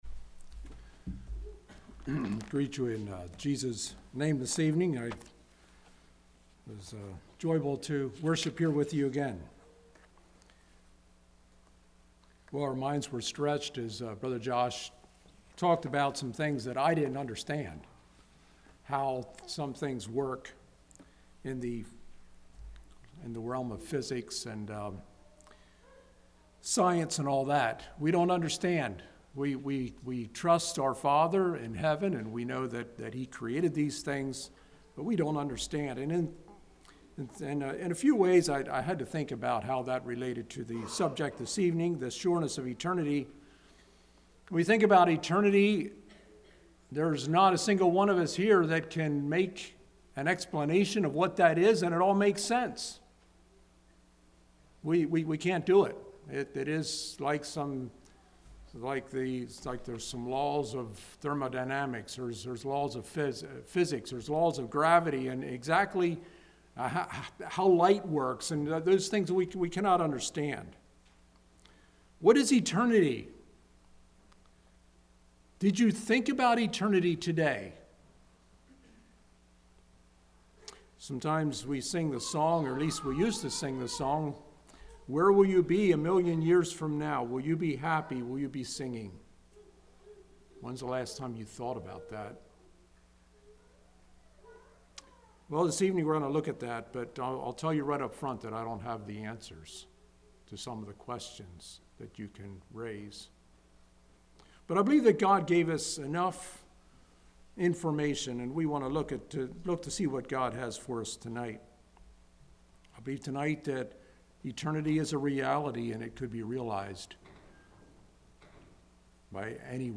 Congregation: Blue Ridge